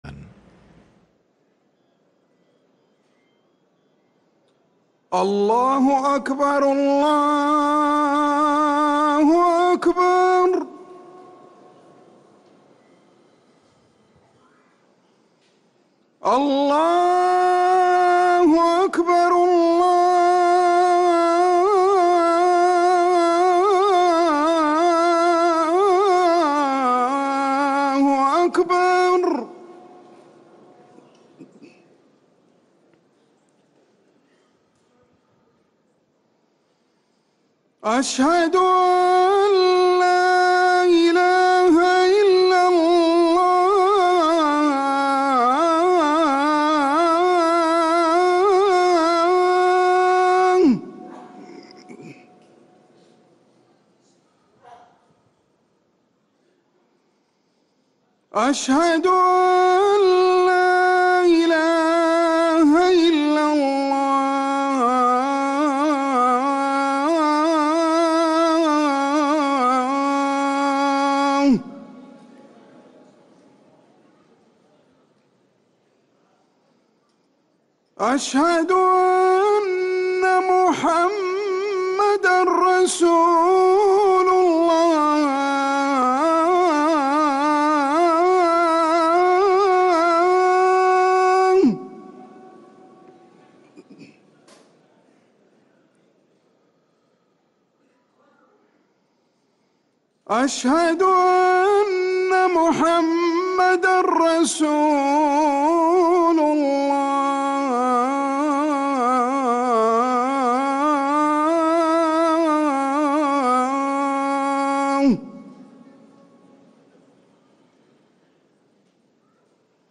أذان العشاء للمؤذن عمر سنبل الخميس 16 رجب 1446هـ > ١٤٤٦ 🕌 > ركن الأذان 🕌 > المزيد - تلاوات الحرمين